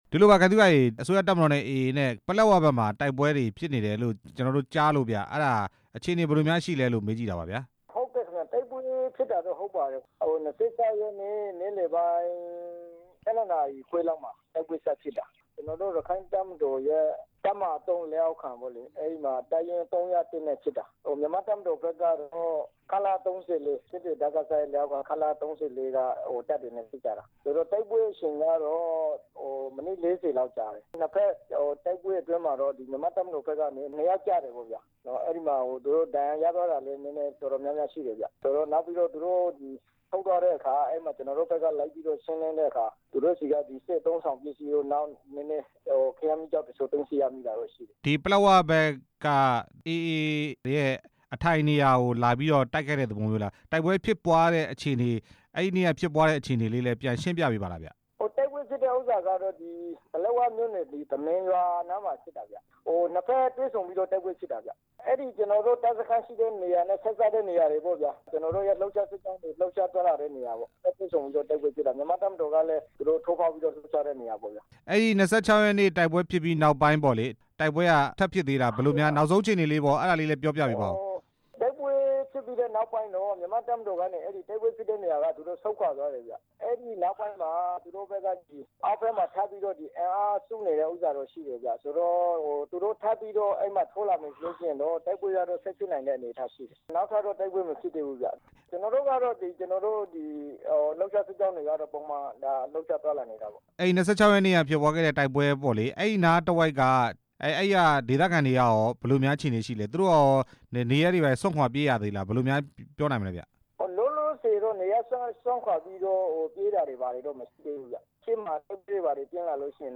တပ်မတော် နဲ့ AA တိုက်ပွဲဖြစ်ပွားတဲ့အကြောင်း မေးမြန်းချက်